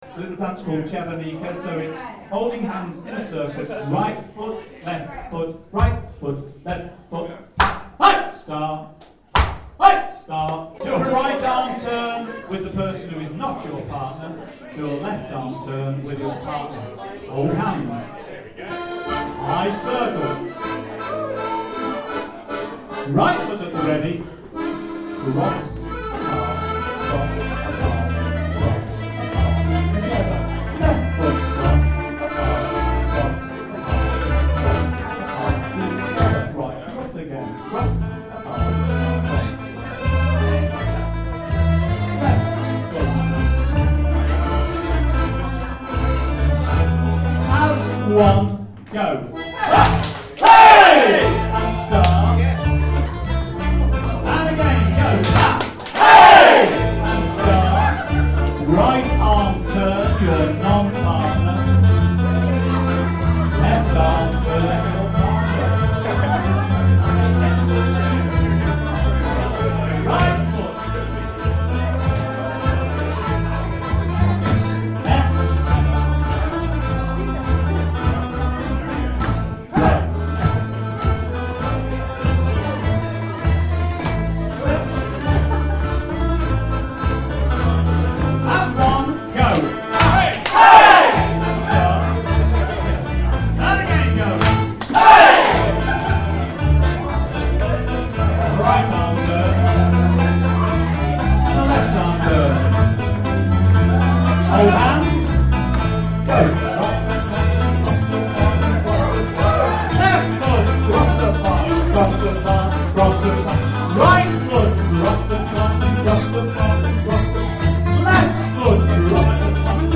Sound samples for JIGABIT Ceilidh and Barn Dance Band and Caller
AUDIO These are samples of Jigabit's dance tunes and also the songs we play between dances.
o_Dance_-_Czeck_Dance_-_with_Caller.mp3